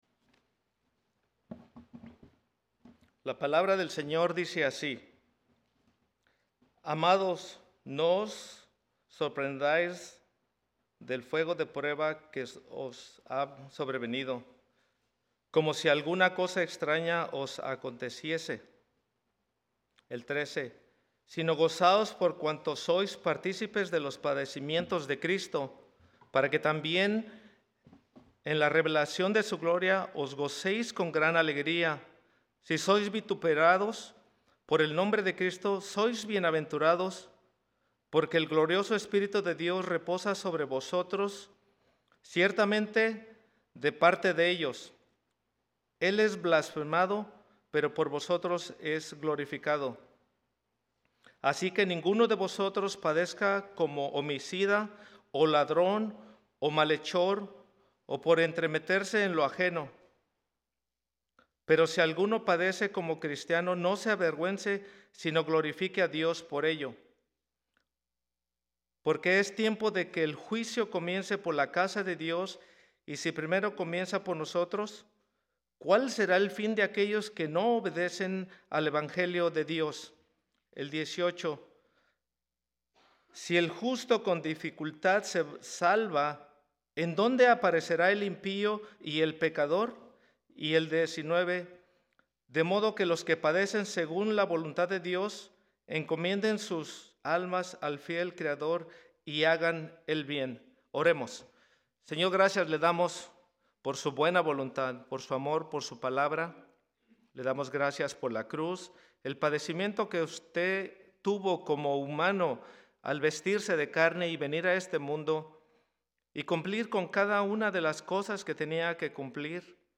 Mensaje
Un mensaje de la serie "Invitado Especial."